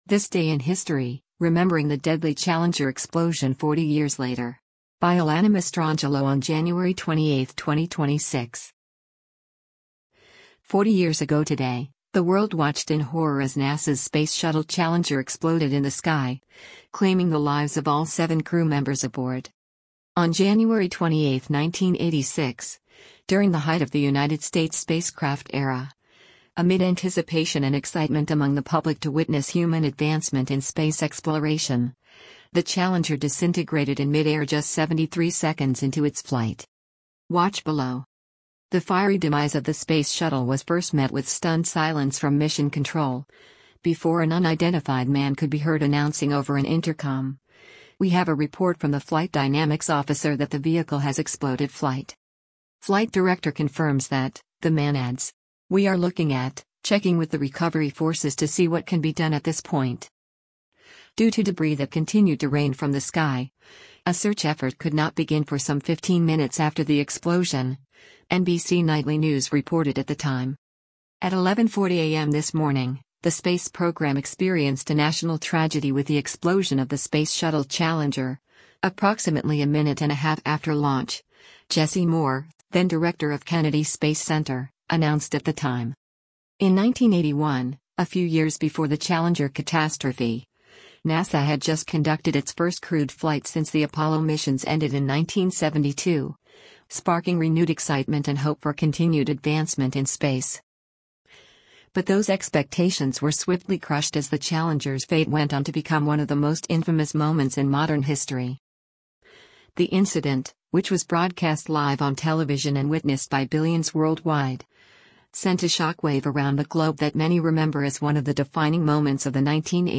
The fiery demise of the space shuttle was first met with stunned silence from Mission Control, before an unidentified man could be heard announcing over an intercom: “We have a report from the flight dynamics officer that the vehicle has exploded flight.”